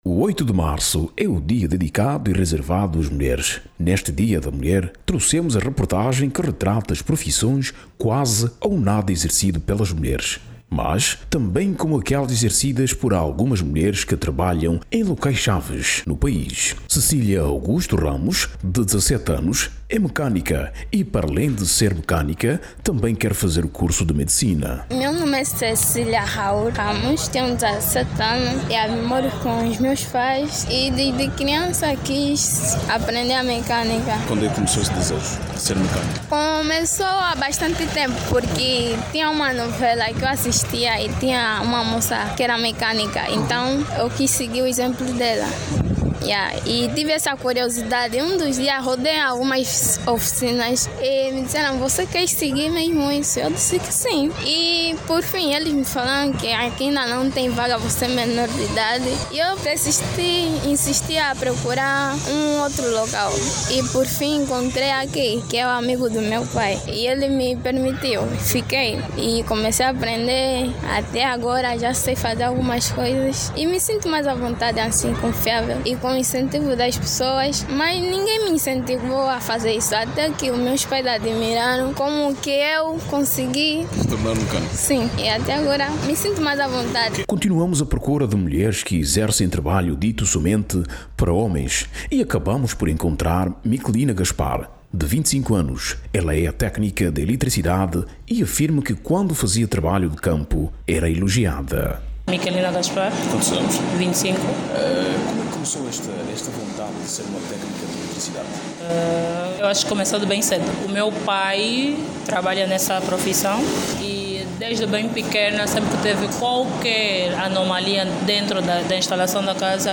A RÁDIO NOVA saiu as ruas e ouviu algumas senhoras no âmbito do Março Mulher, onde falaram sobre o seu dia-a-dia.